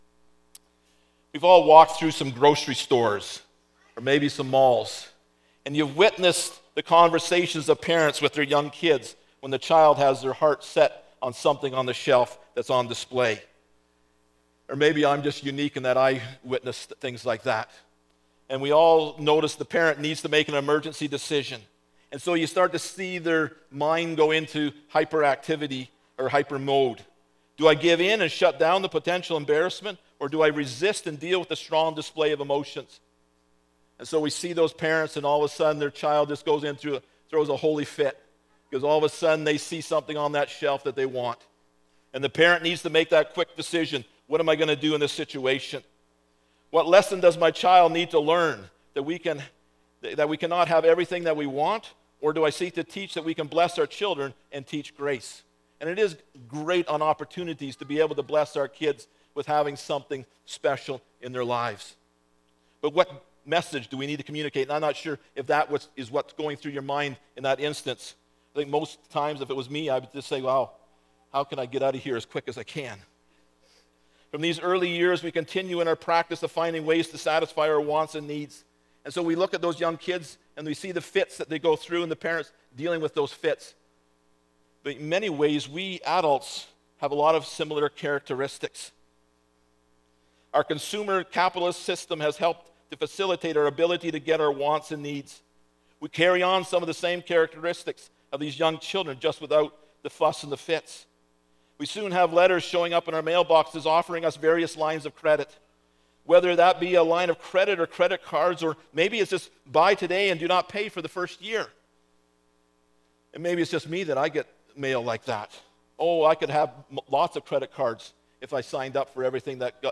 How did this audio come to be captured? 1 Tim 4:6-11 Service Type: Sunday Morning Topics: Finances